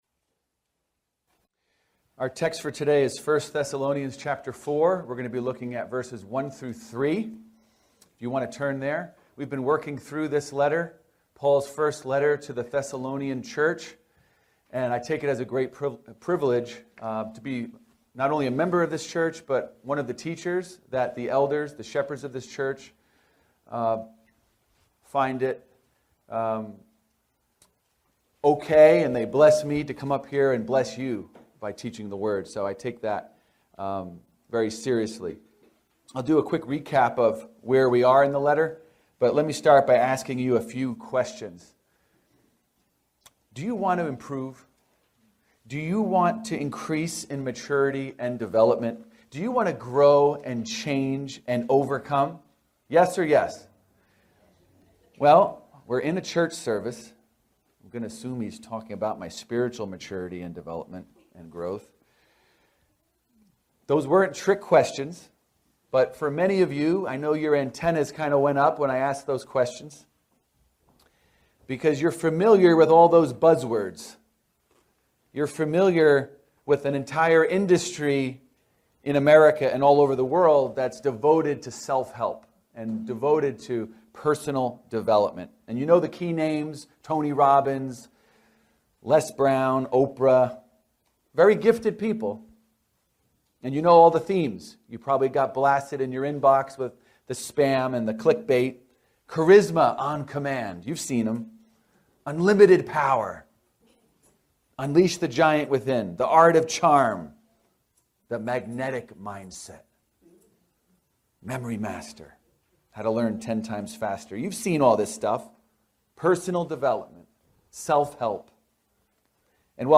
Continuous Improvement | SermonAudio Broadcaster is Live View the Live Stream Share this sermon Disabled by adblocker Copy URL Copied!